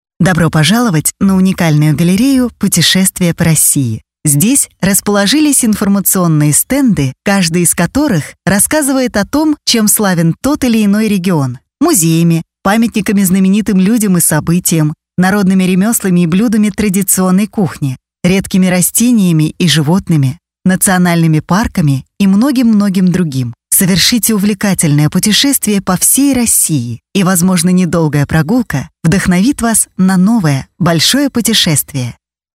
ethnМягкий женский голос для чтения (028)